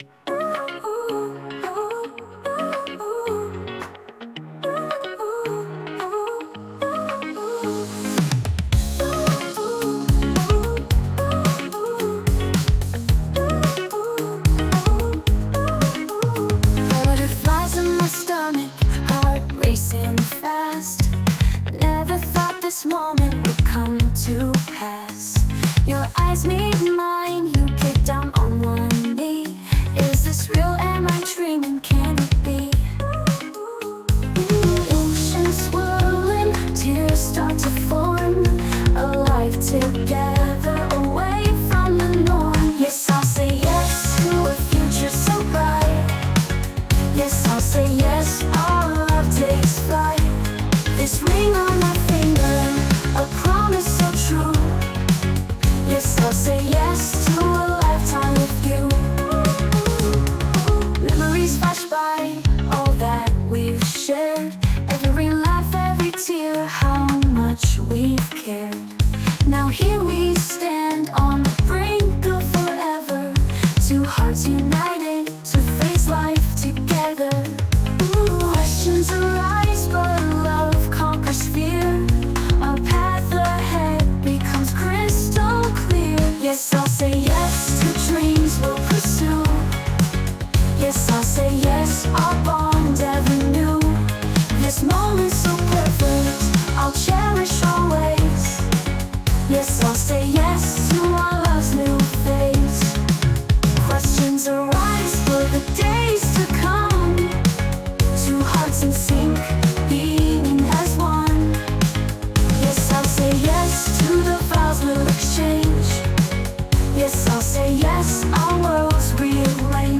洋楽女性ボーカル著作権フリーBGM ボーカル
女性ボーカル（洋楽・英語）曲です。